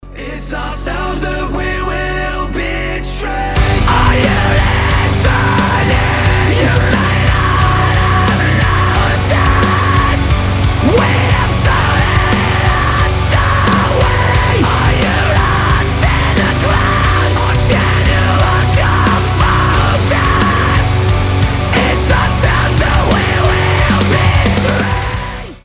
• Качество: 320, Stereo
громкие
мощные
брутальные
Metalcore
Post-Hardcore
гроулинг